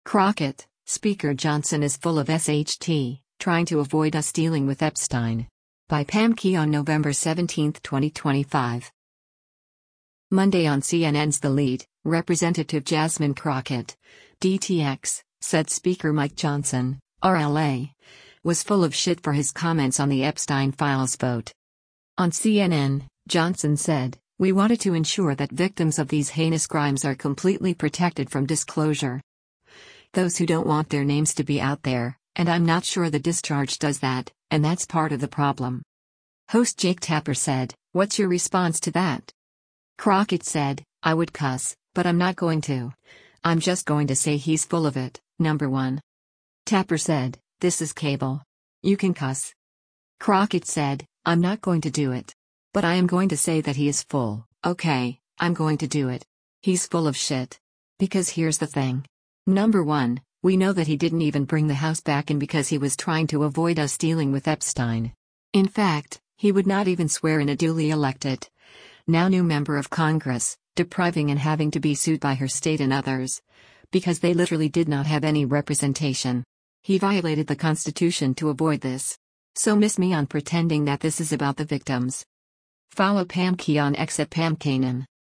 Monday on CNN’s “The Lead,” Rep. Jasmine Crockett (D-TX) said Speaker Mike Johnson (R-LA) was “full of shit” for his comments on the Epstein files vote.